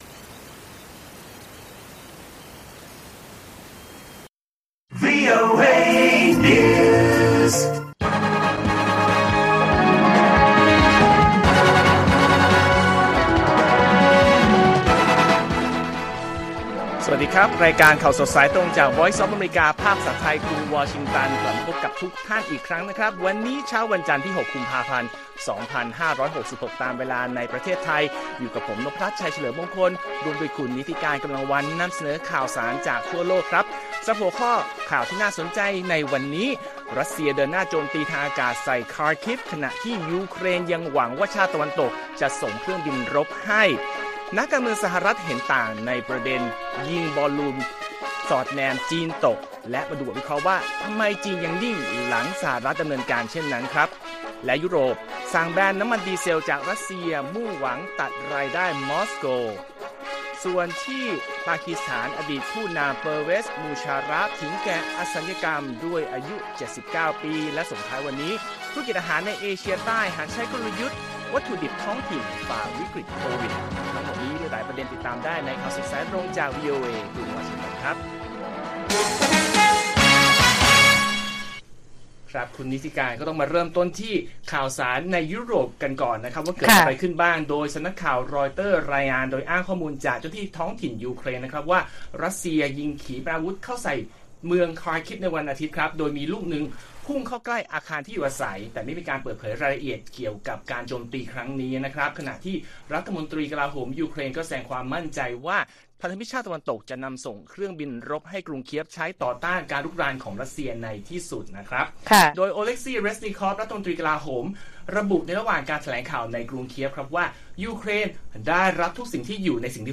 ข่าวสดสายตรงจากวีโอเอไทย จันทร์ ที่ 6 ก.พ. 66